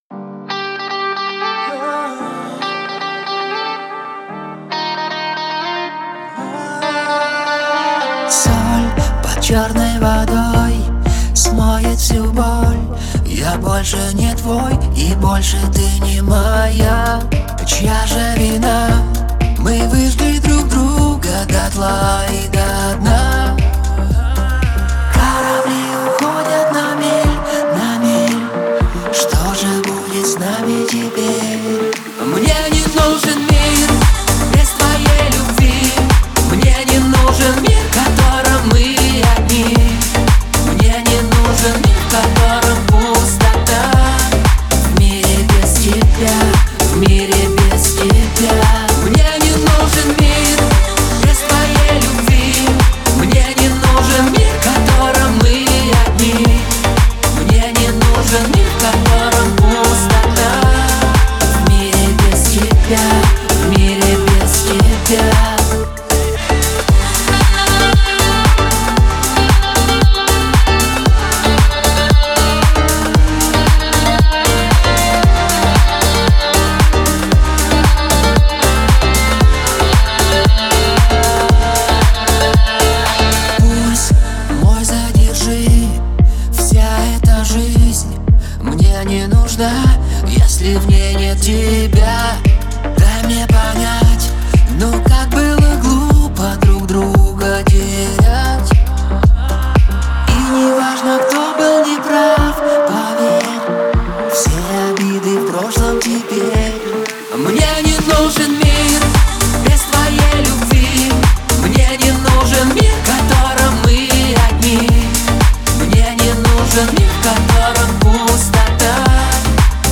это романтическая баллада в жанре поп-музыки
Звучание композиции отличается мелодичностью и гармонией